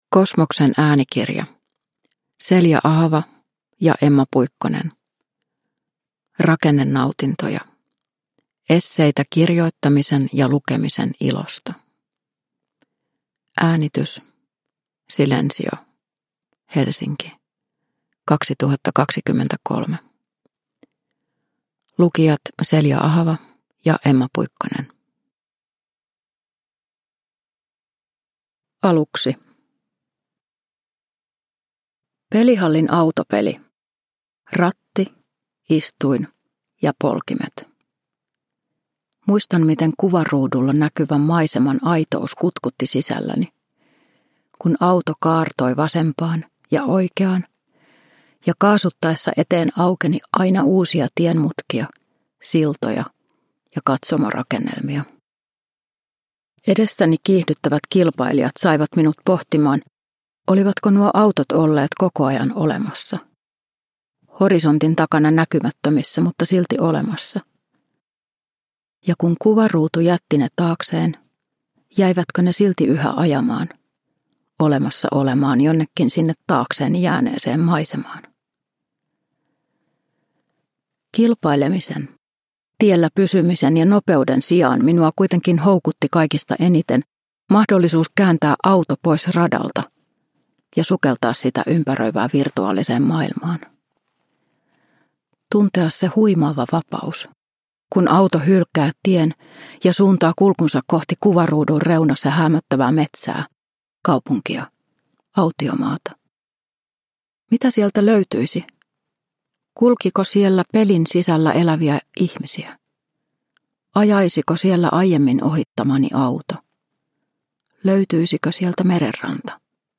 Rakennenautintoja – Ljudbok – Laddas ner